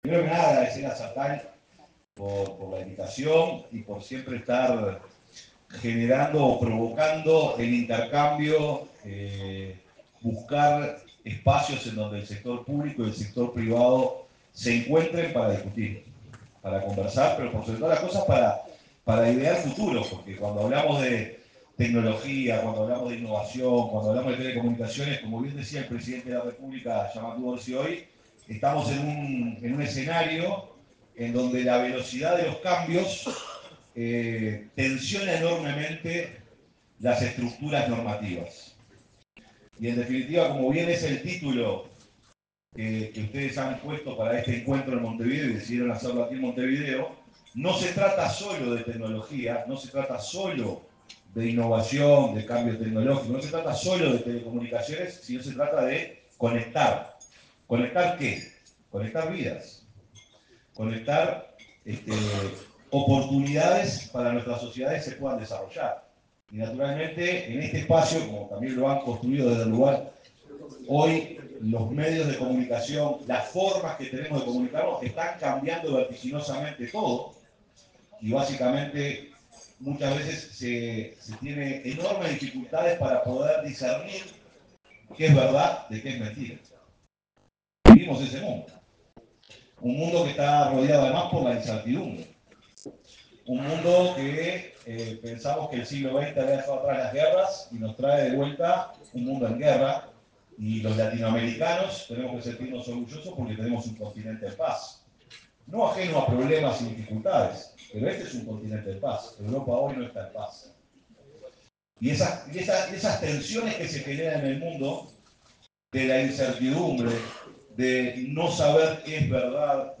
Palabras del secretario de Presidencia, Alejandro Sánchez
Durante el encuentro Justicia y Telecomunicaciones, el secretario de la Presidencia, Alejandro Sánchez, se refirió a la celeridad de los cambios